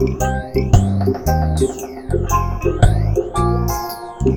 PHASERLOOP-L.wav